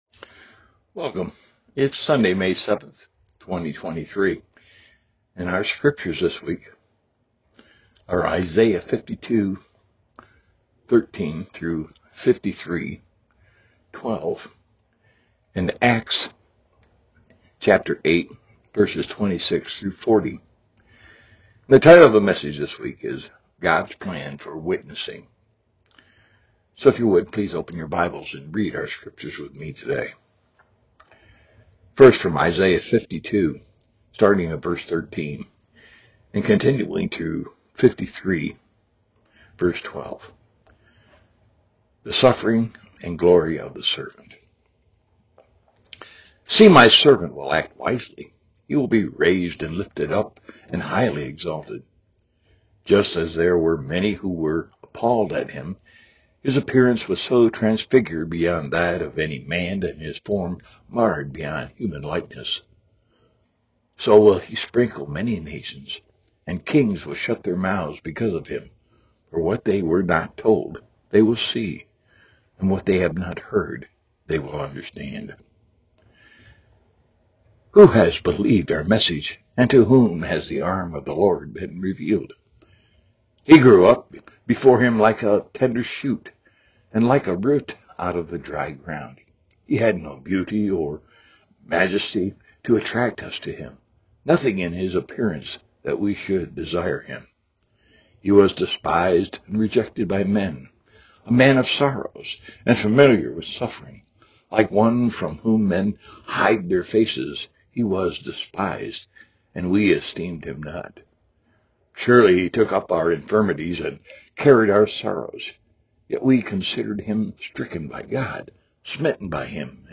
Worship Service – May 7, 2023 « Franklin Hill Presbyterian Church